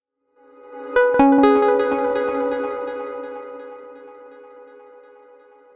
speaker-test-0fcf8dde.mp3